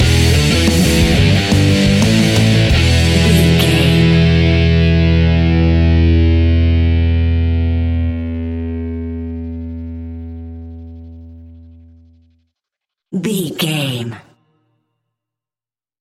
Hard and Powerful Metal Rock Music Cue Stinger.
Epic / Action
Aeolian/Minor
hard rock
heavy metal
distortion
Rock Bass
heavy drums
distorted guitars
hammond organ